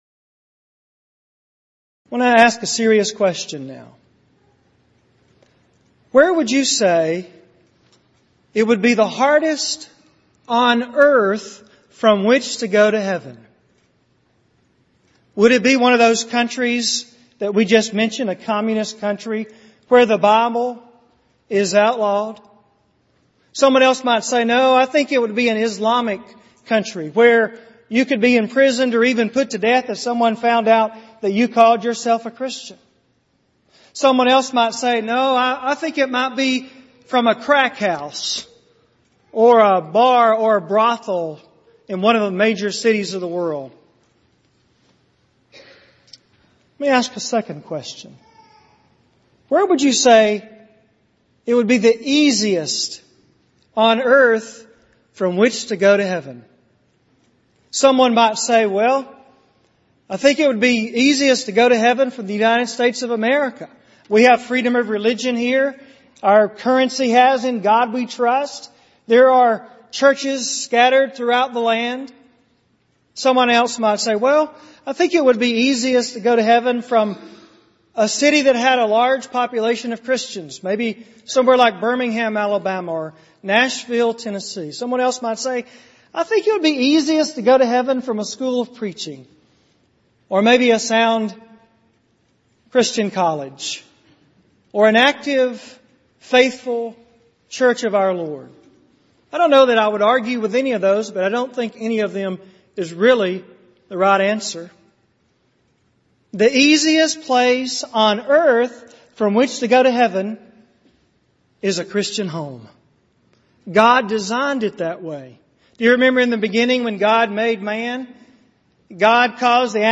Event: 28th Annual Southwest Lectures
If you would like to order audio or video copies of this lecture, please contact our office and reference asset: 2009Southwest15